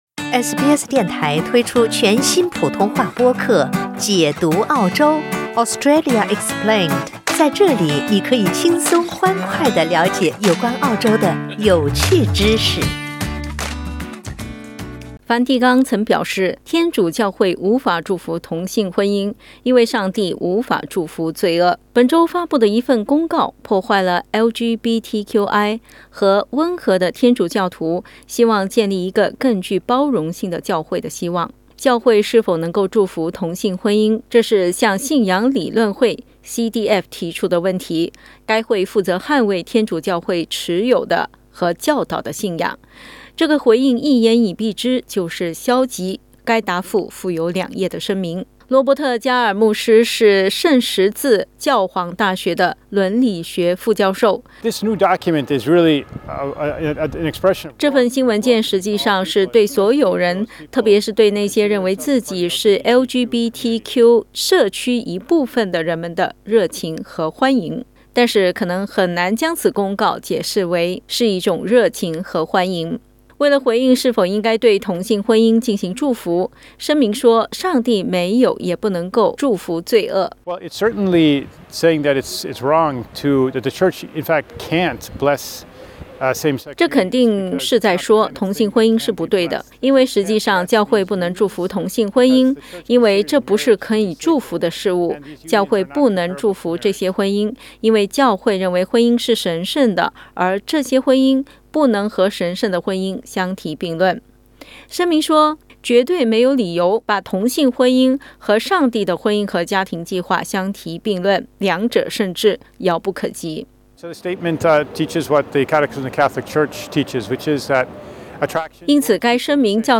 （請聽報道） 澳大利亞人必鬚與他人保持至少 1.5 米的社交距離，請查看您所在州或領地的最新社交限制措施。